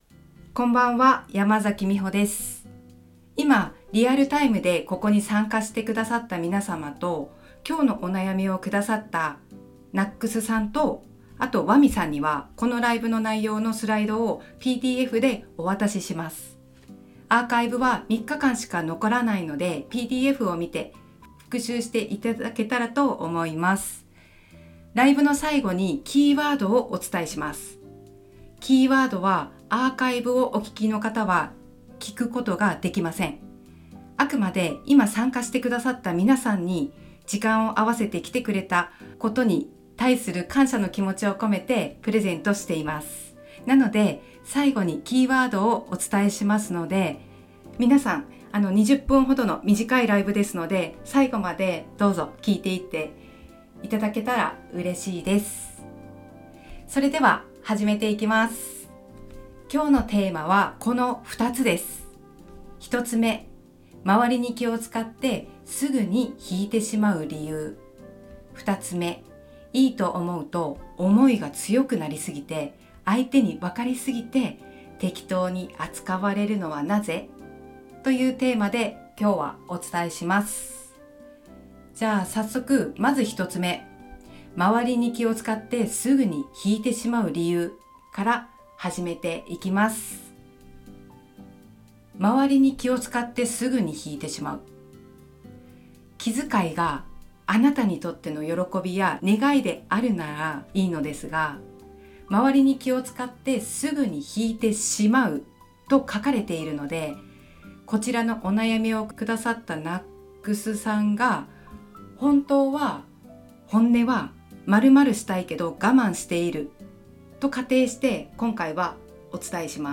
ライブトーク♡25-2-24